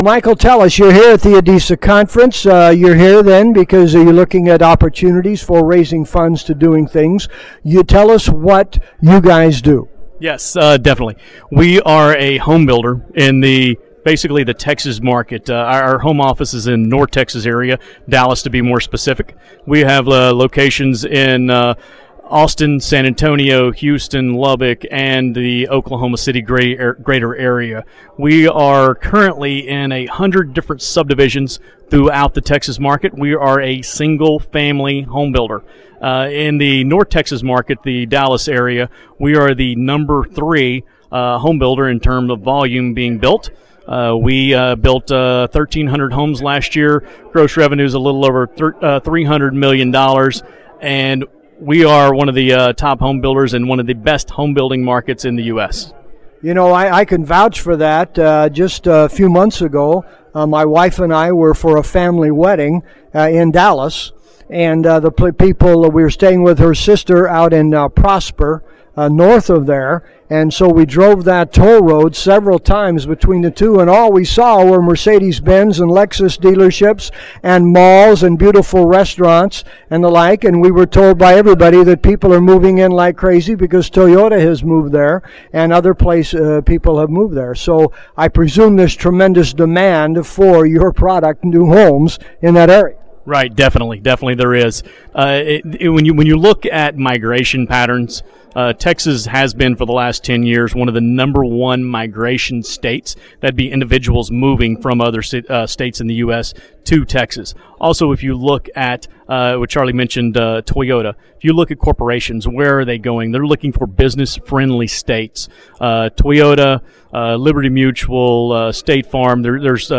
This market is red hot, with loads of opportunity. This short interview occured at the ADISA Conference in San Diego.